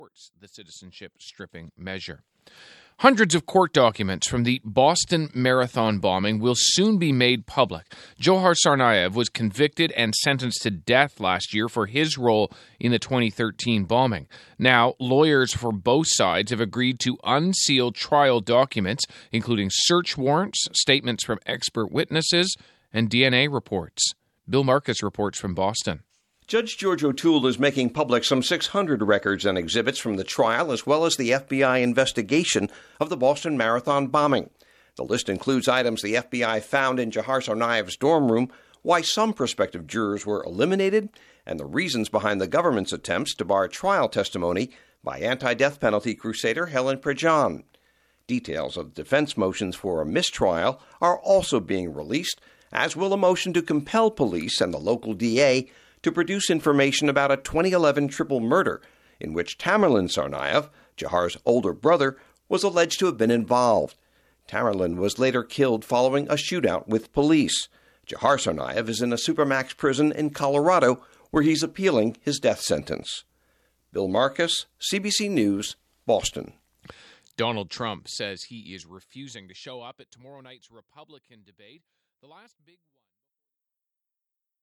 Featured - CBC(TORONTO, JAN 27) WORLD REPORT, 7AM –